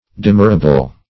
Search Result for " demurrable" : The Collaborative International Dictionary of English v.0.48: Demurrable \De*mur"ra*ble\ (d[-e]*m[^u]r"r[.a]*b'l), a. That may be demurred to.